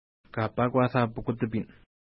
Pronunciation: ka:pa:kwa:ta:h pukutəpi:n